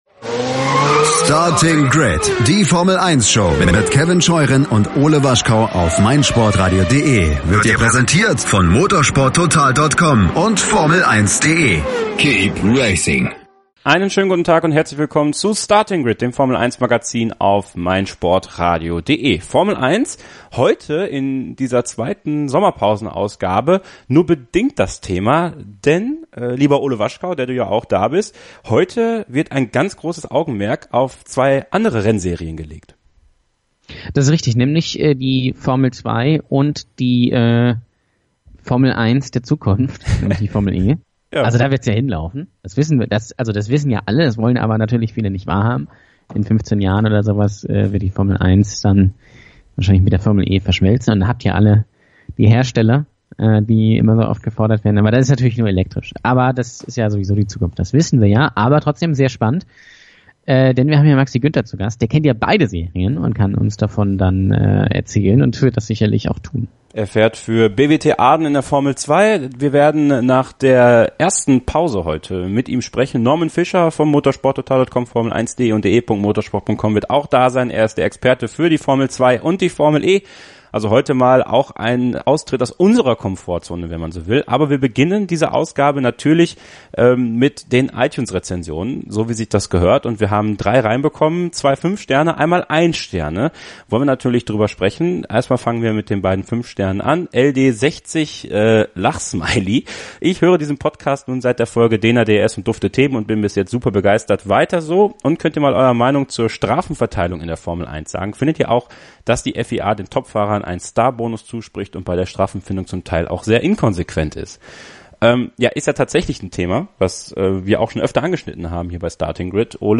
Herzlich Willkommen zum zweiten Sommerinterview des Jahres 2018.